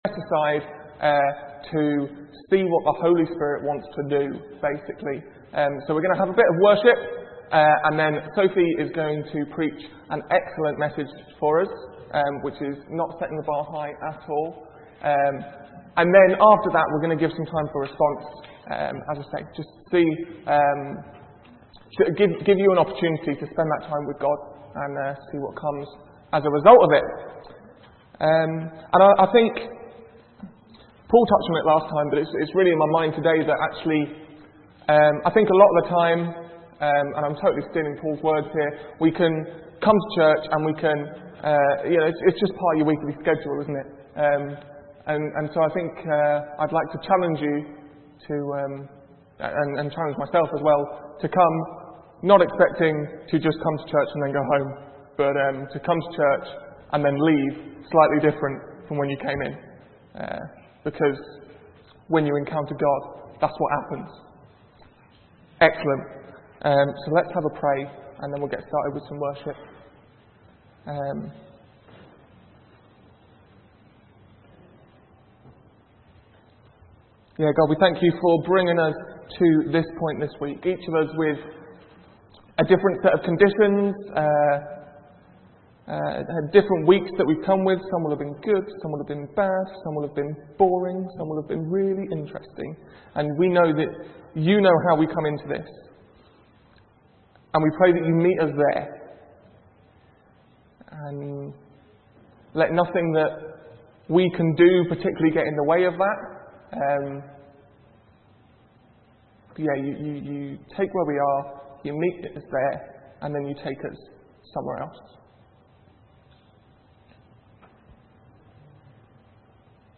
A message from the series
From Service: "6.30pm Service"